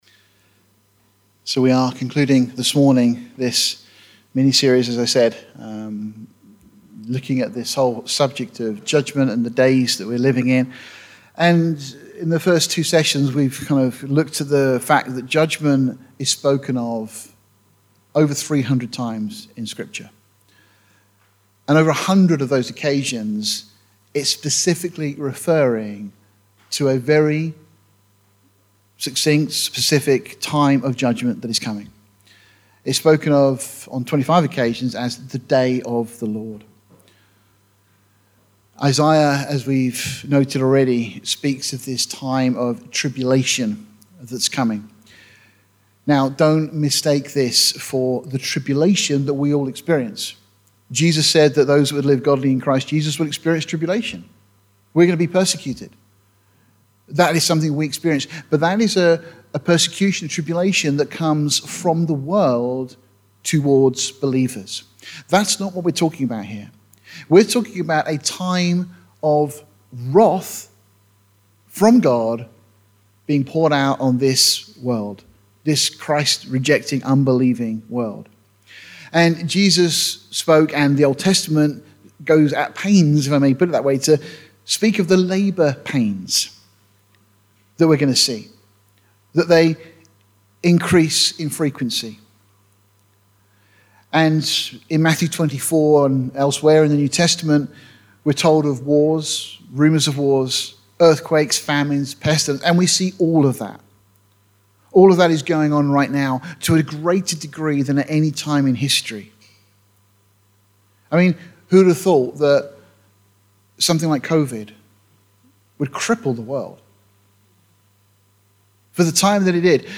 It’s curious that after this sermon we should hear on the news of the record-breaking earthquake in eastern Russia!